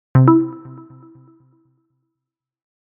Scifi 9.mp3